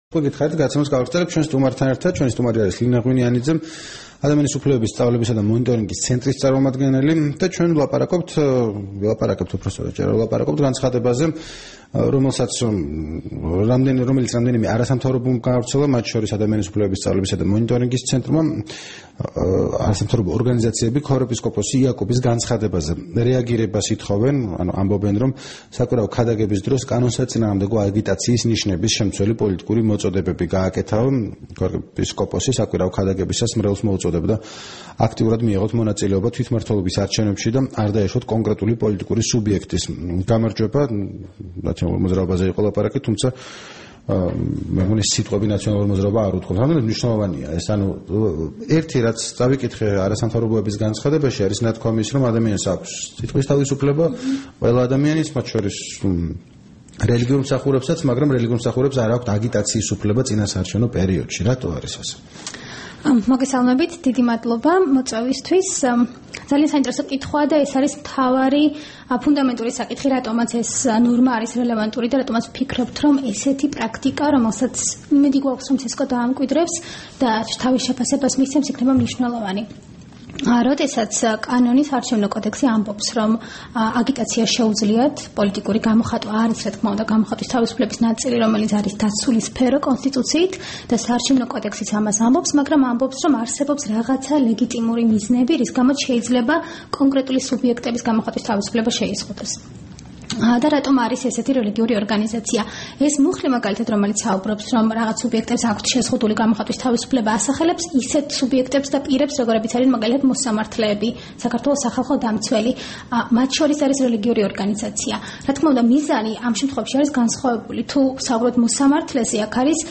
რადიო თავისუფლების თბილისის სტუდიაში